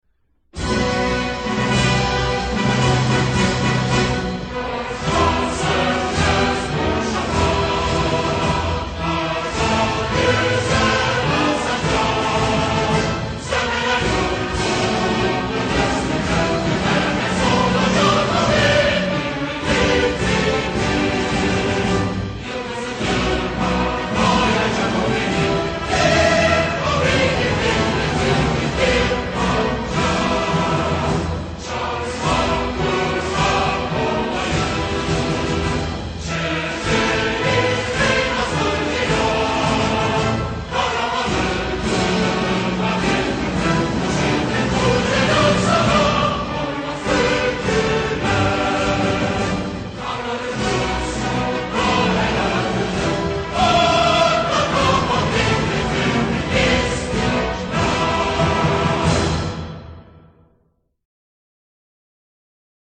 Bu versiyon, İstiklal Marşı'nın başında "ti" sesi olmadan başlayan versiyonudur.